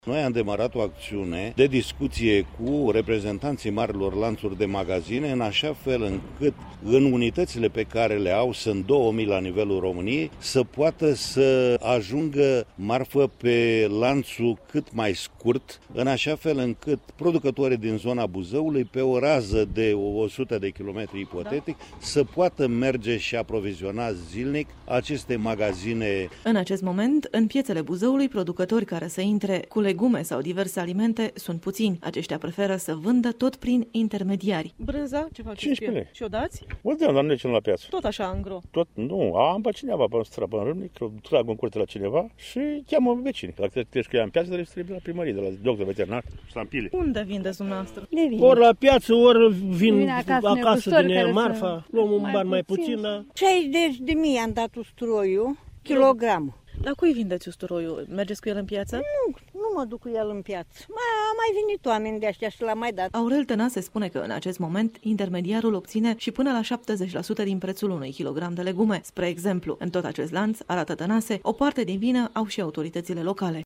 El a declarat pentru RRA că în pieţe sunt puţini producători, care preferă să-şi vândă marfa prin intermediari şi a precizat că se fac demersuri pentru introducerea produselor locale în hipermarketuri: